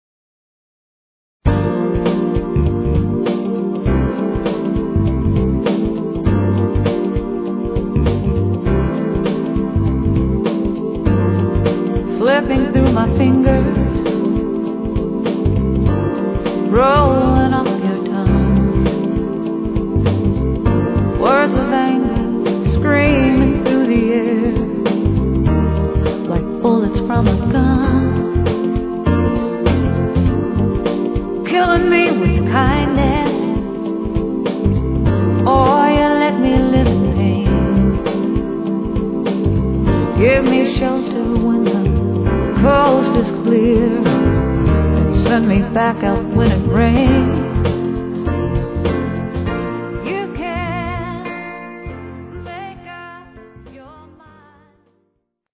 nova, and jazz pop songs with an easy listening sound.